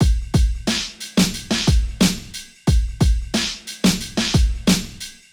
South Philly Drum Loop.wav